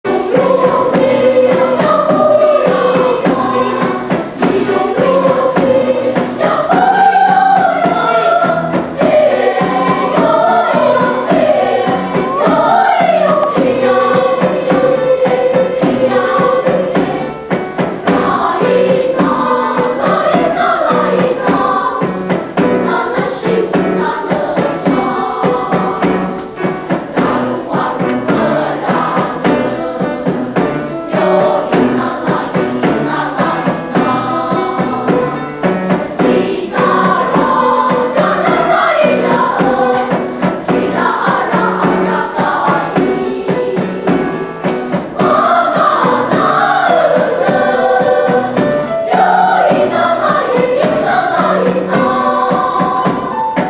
團體歌唱時，仍以領唱與和腔的唱法最為普遍。
排灣族歌唱形式大致有三種：一是兩部不和協之異音唱法。二是具有持續低音之唱法。三是以一個主題作多種變化的變奏唱法。